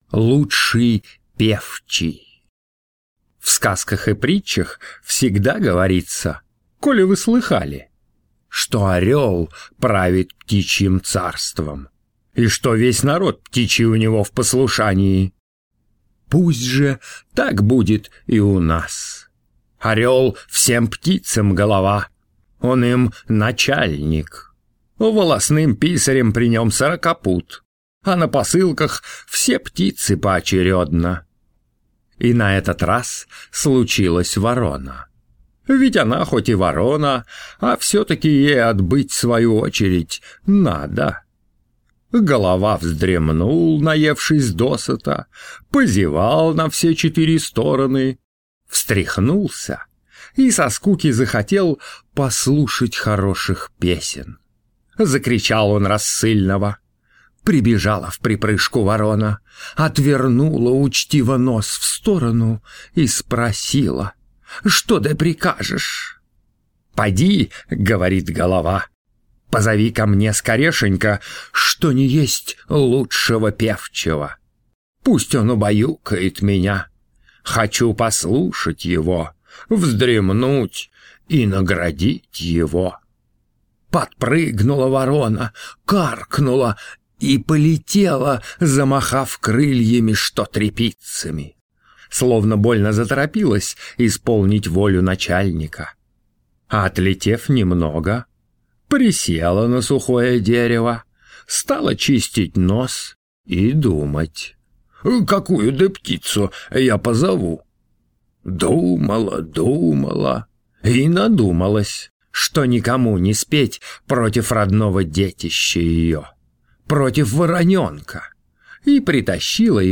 На данной странице вы можете слушать онлайн бесплатно и скачать аудиокнигу "Лучший певчий" писателя Владимир Даль.